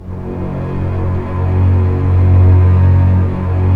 Index of /90_sSampleCDs/Roland LCDP13 String Sections/STR_Orchestral p/STR_Orch. p Slow